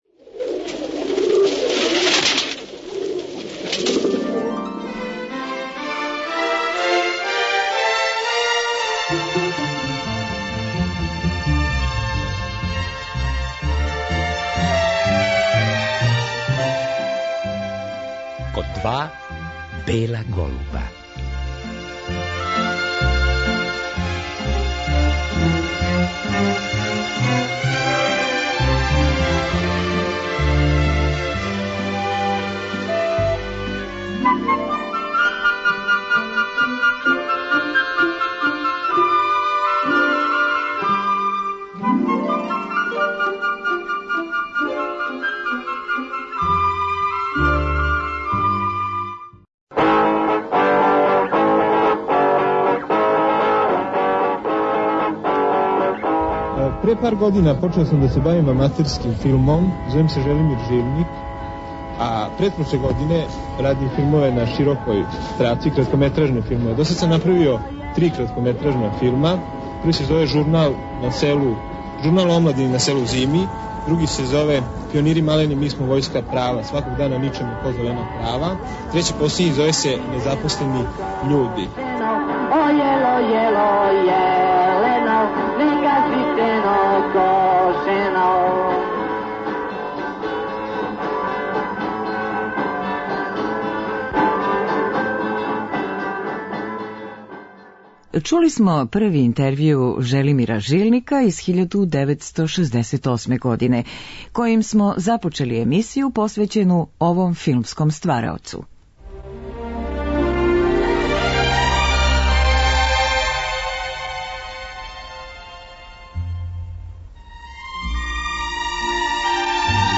Чућемо разговор са Желимиром Жилником